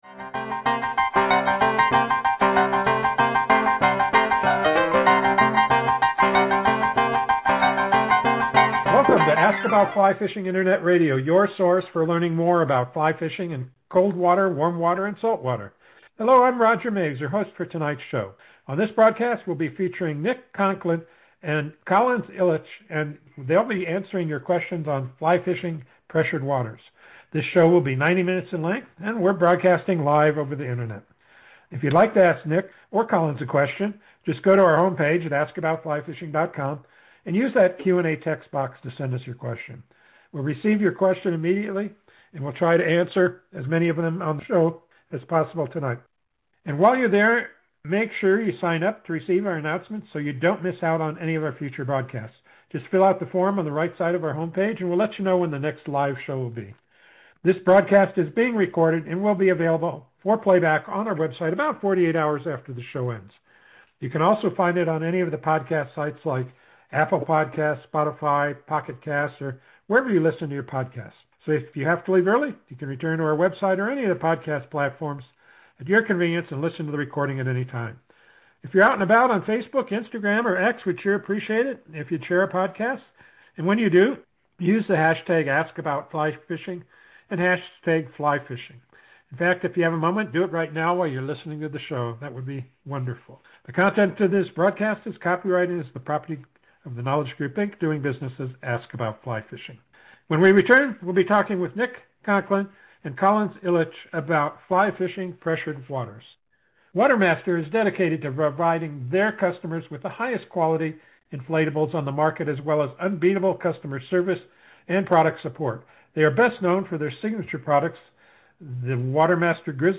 Just a few of the questions asked and answered during the interview: